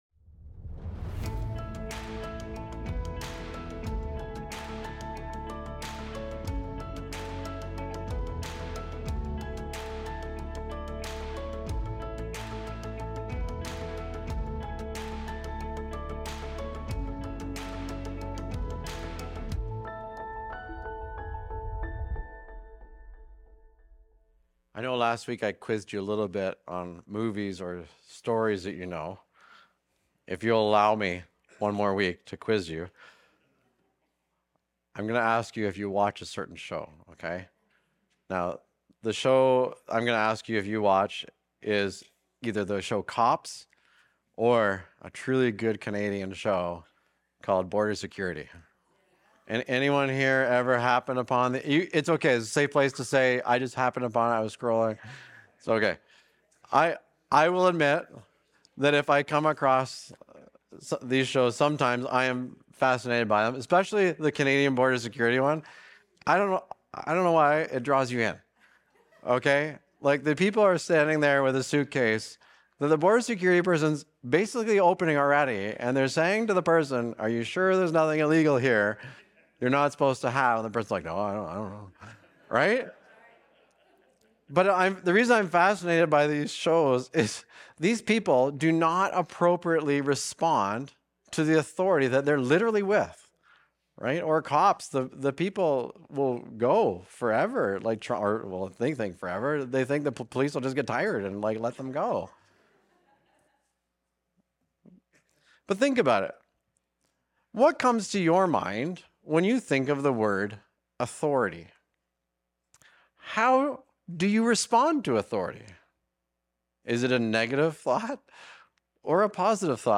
Recorded Sunday, February 8, 2026, at Trentside Fenelon Falls.